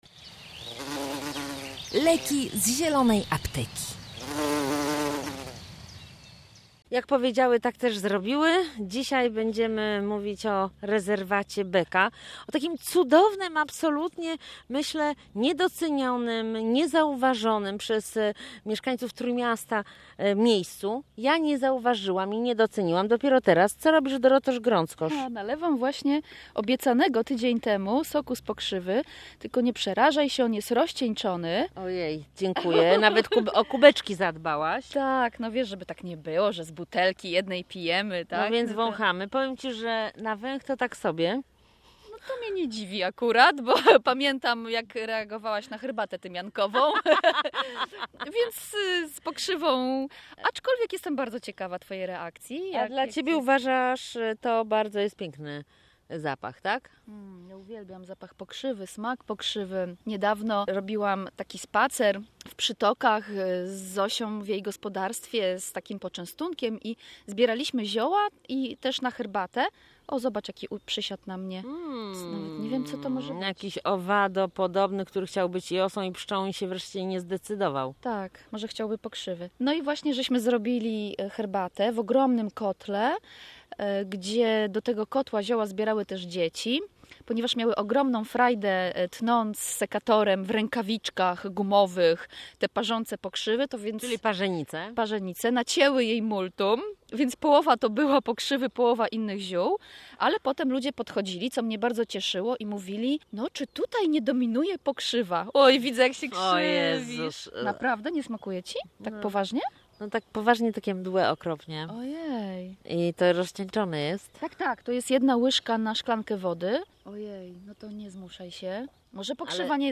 Panie rozmawiają także o zaletach i walorach fauny i flory rezerwatu Beka i żałują, że nie wzięły ze sobą lornetki do podglądania ptactwa.